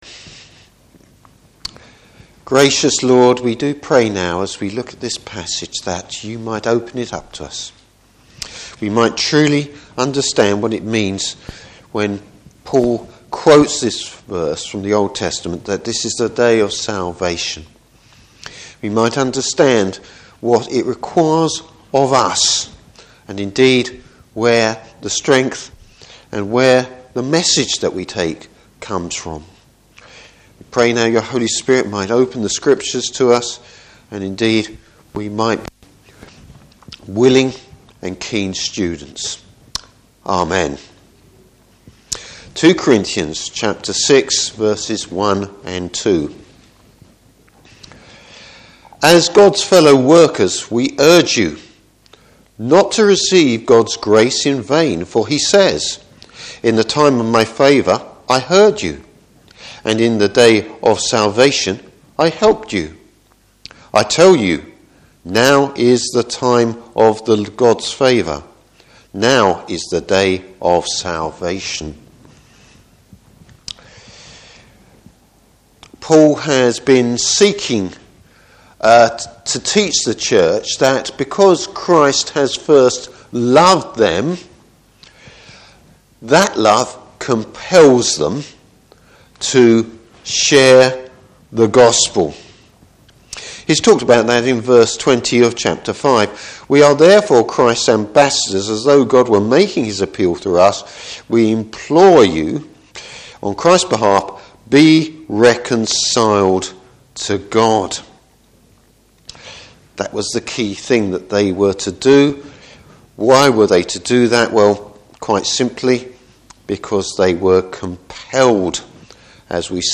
Service Type: Morning Service There’s no time to waste!